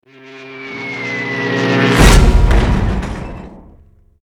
AA_drop_boat.ogg